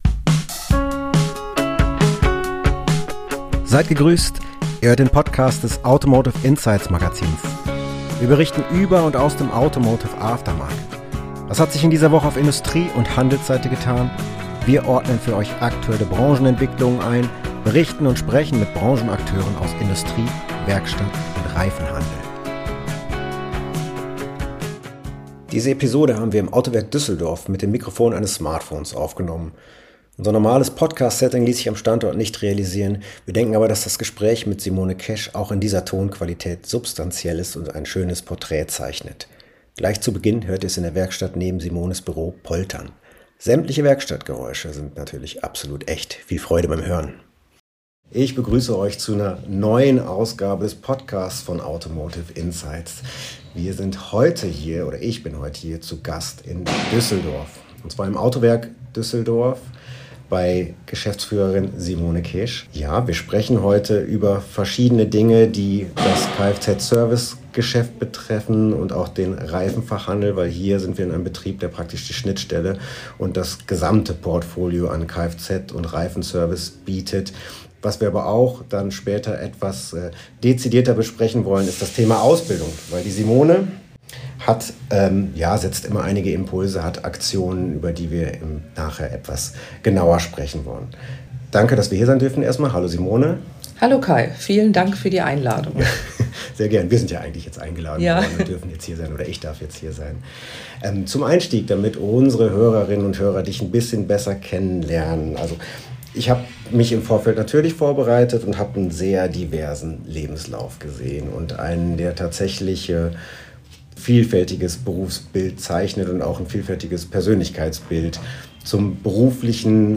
Interview ~ Automotive Insights Magazin